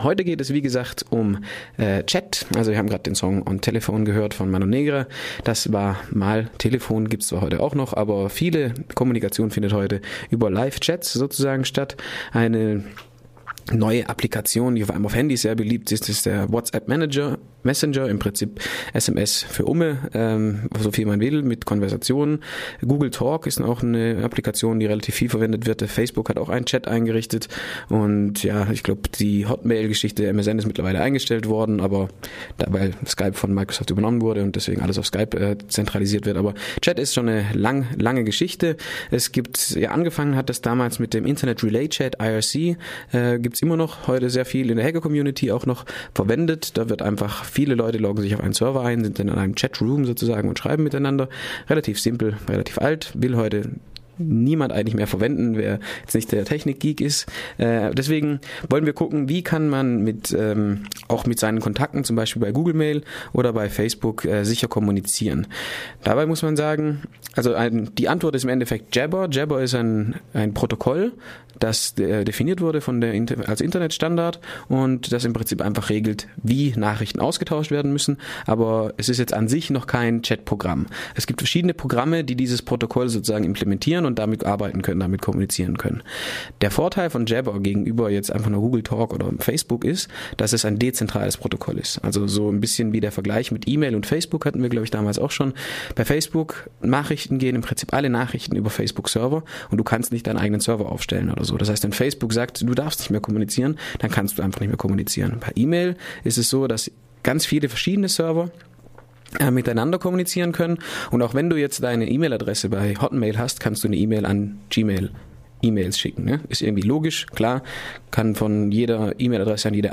Die III. Ausgabe unserer PRISM-Break Reihe im Morgenradio.